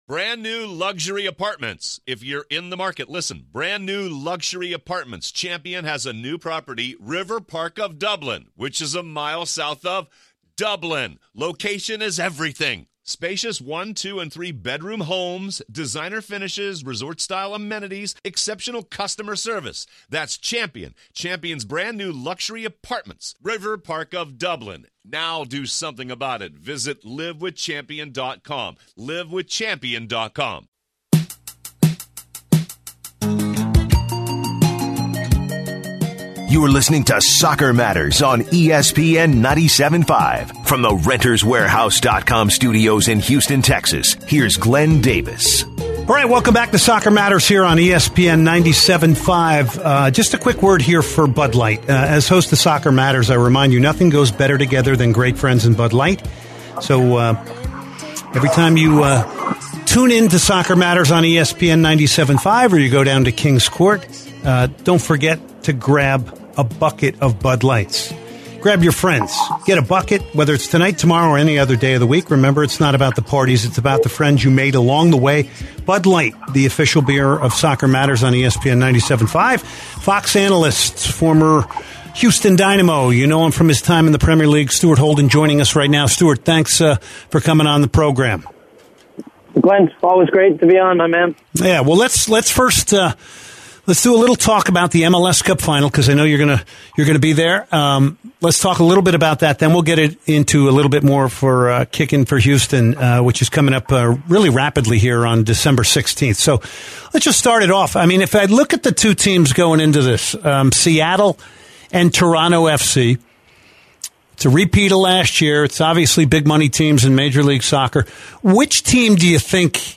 12/04/2017 Stuart Holden Interview